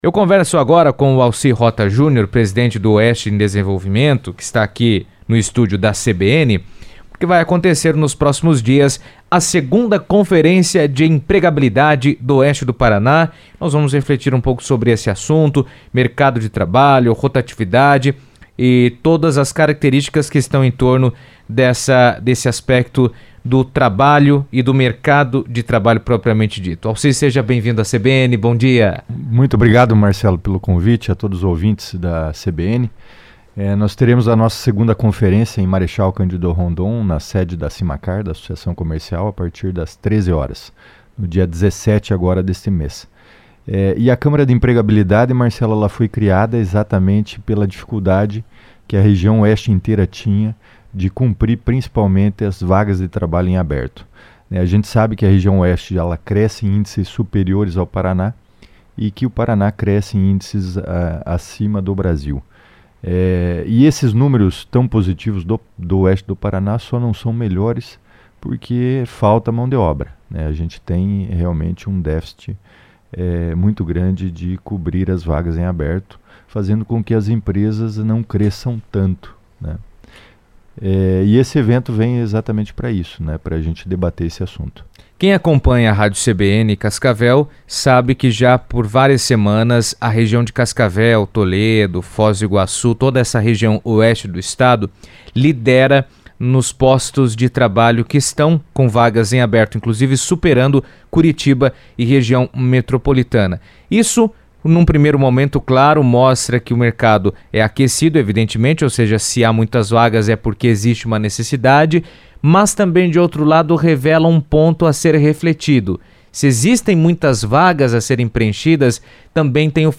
nos estúdios da CBN.